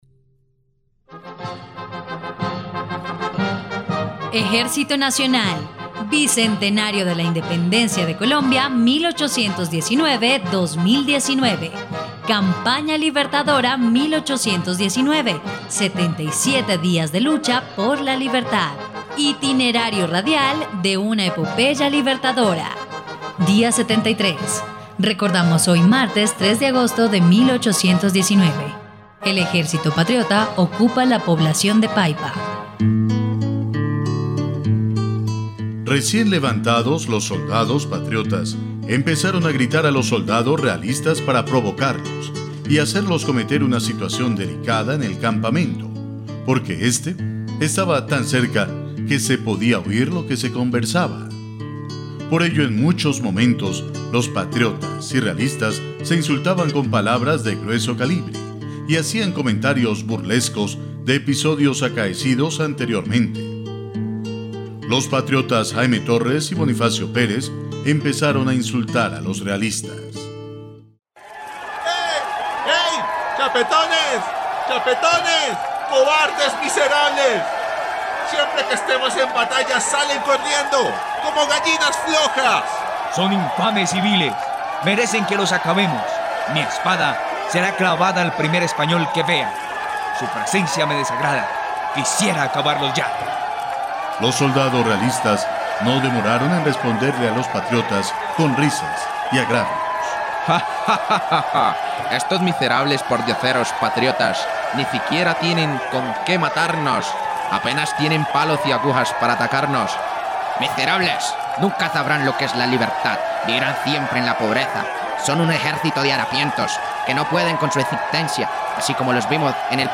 dia_73_radionovela_campana_libertadora.mp3